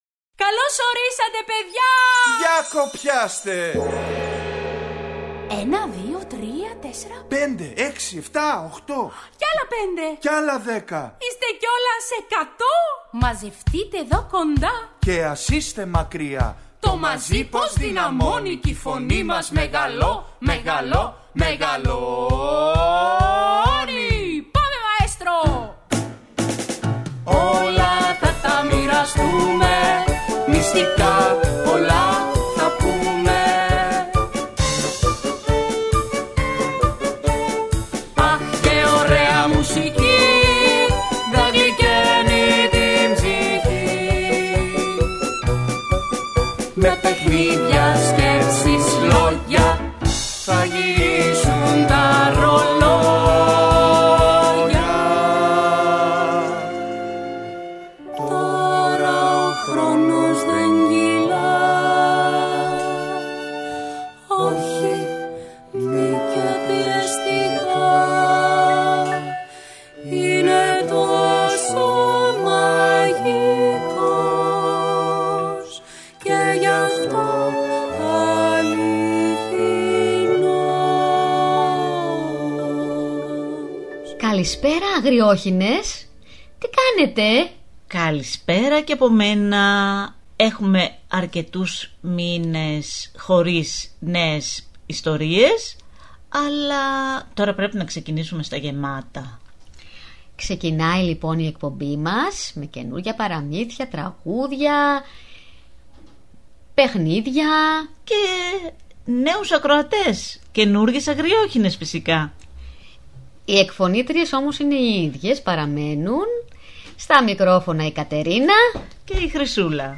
Ακούστε στην παιδική εκπομπή ‘’Οι Αγριόχηνες’’ το παραμύθι «Η Μαύρη πεταλούδα» της Έρης Ρίτσου.